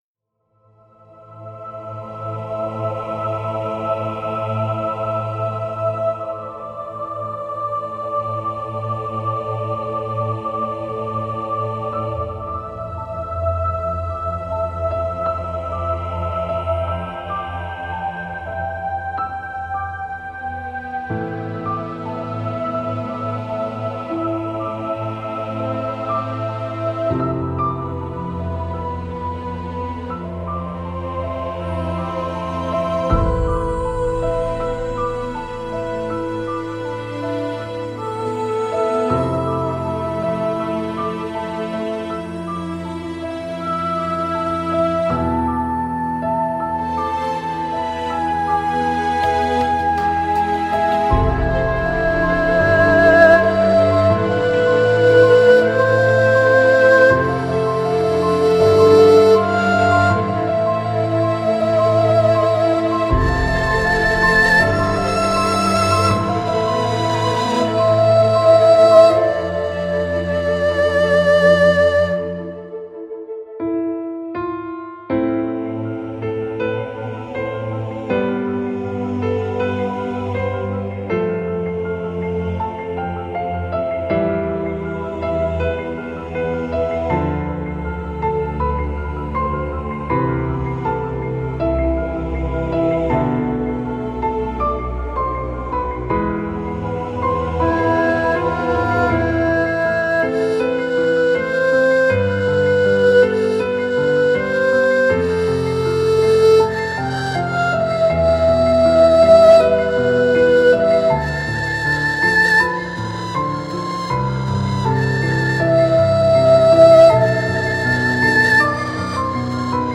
Музыка скорби и памяти, песни о потере и печали